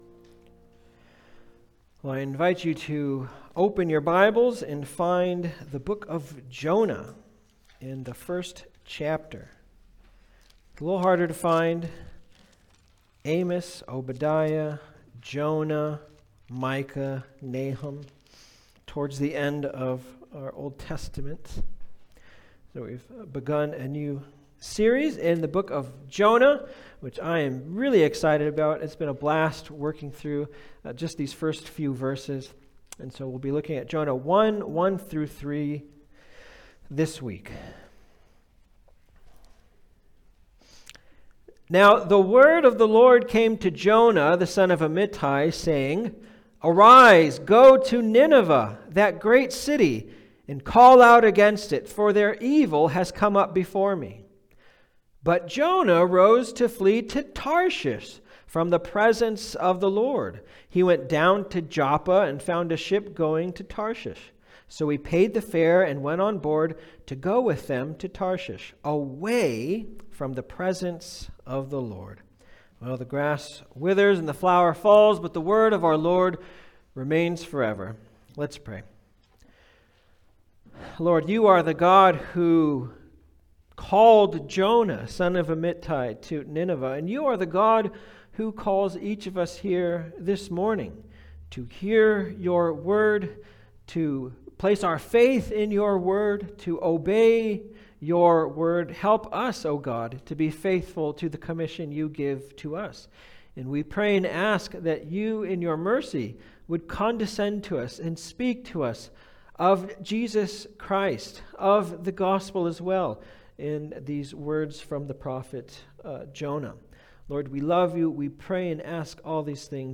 Jonah 2025 Passage: Jonah 1:1-3 Service Type: Sunday Service « Jonah’s Commission What Does The Lord Require?